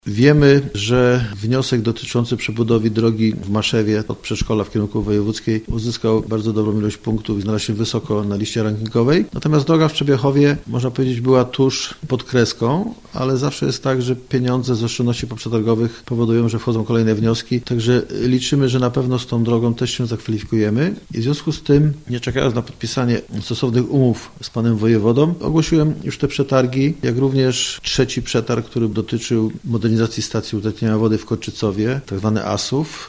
– My składaliśmy wnioski na budowę dróg z Rządowego Programu Dróg Samorządowych – mówi Dariusz Jarociński, wójt Maszewa: